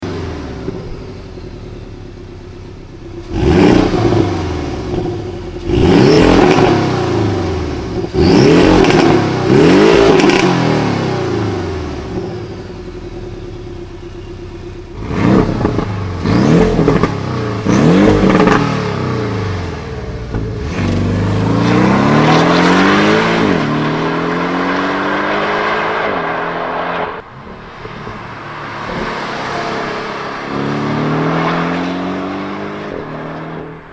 ENGINE SIZE 2.7 L flat-6